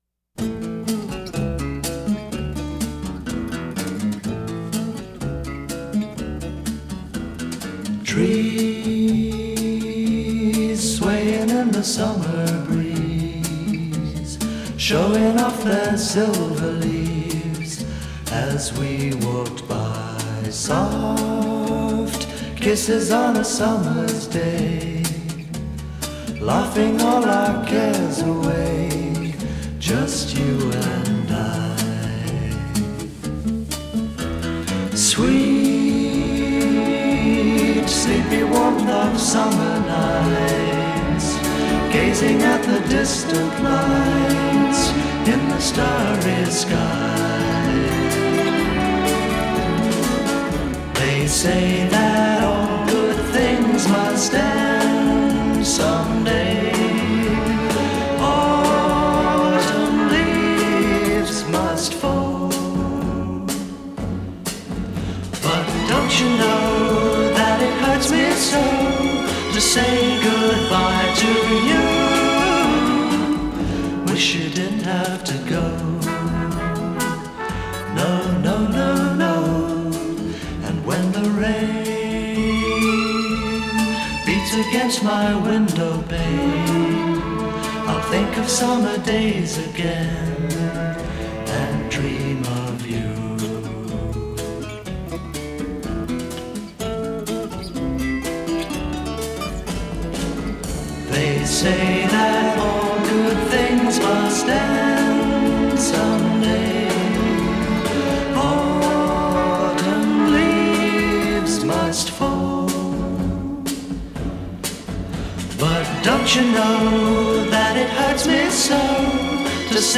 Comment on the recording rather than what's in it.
This is the stereo version.